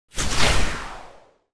Free Frost Mage - SFX
Ice_casting_55.wav